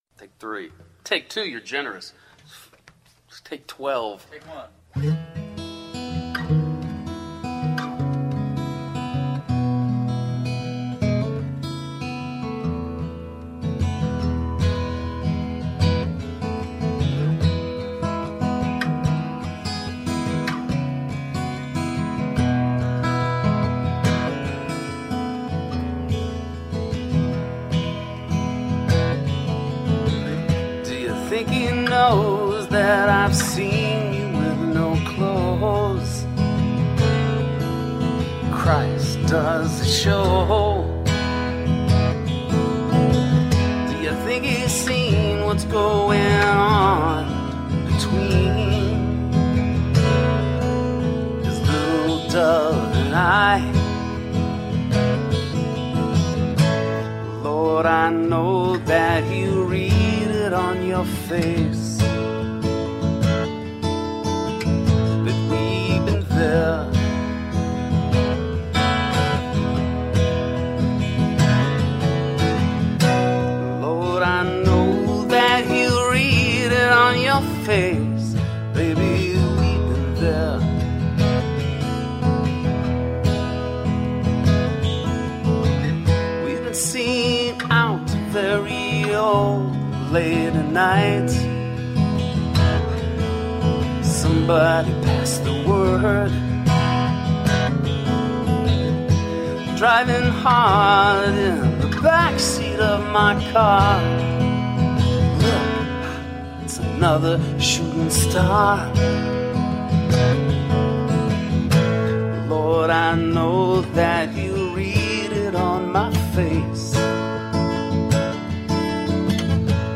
Tagged as: Alt Rock, Rock, Folk